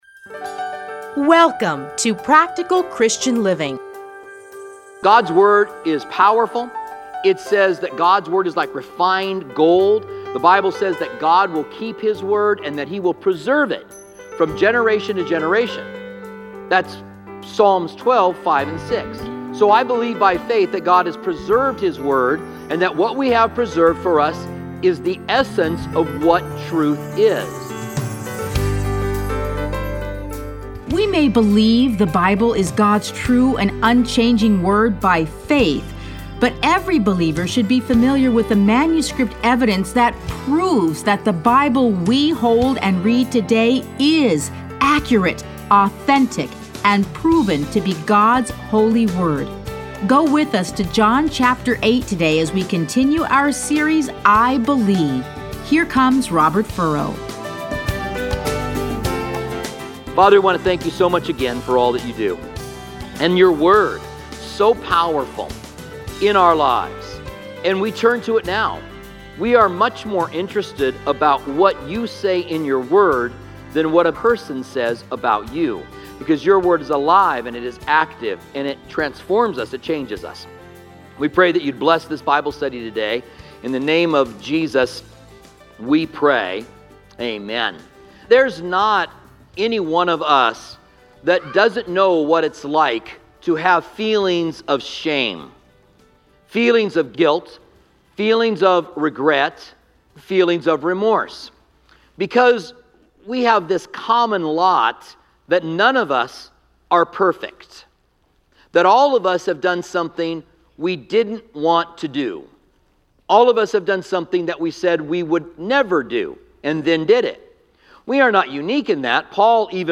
Listen to a teaching from John 8:1-11.